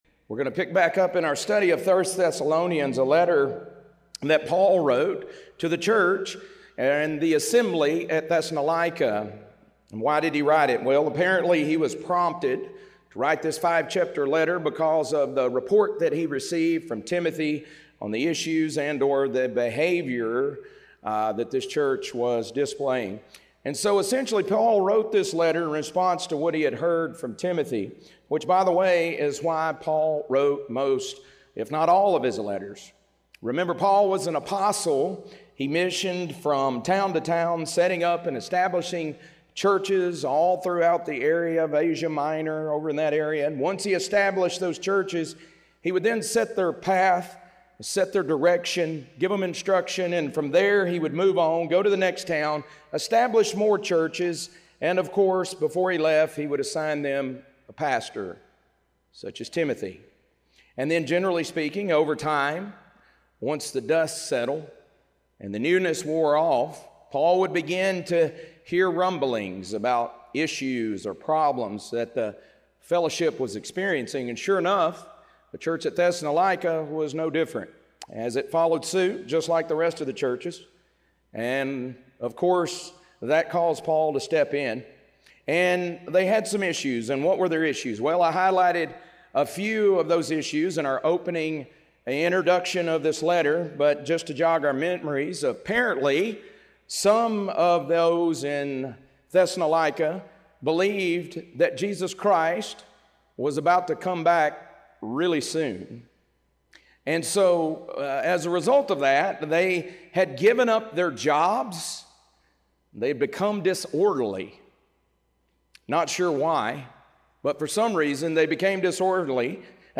1 Thessalonians - Lesson 1C | Verse By Verse Ministry International